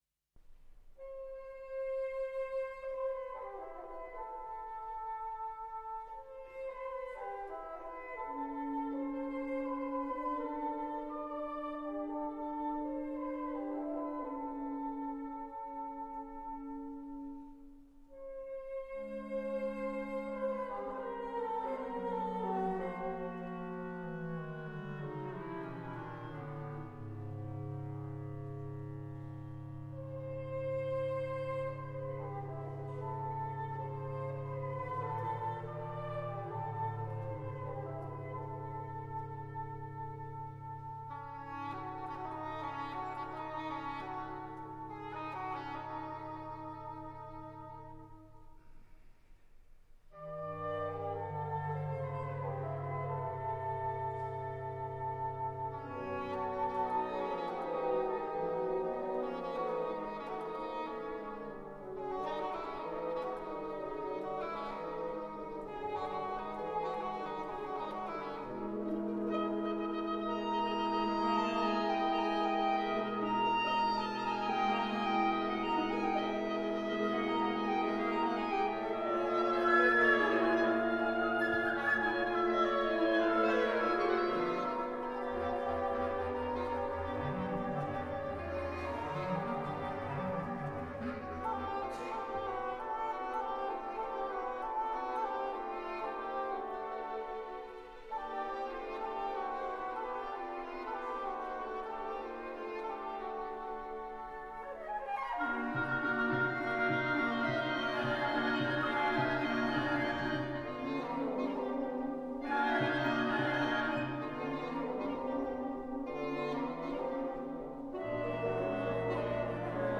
Strvinksky Sacre live concert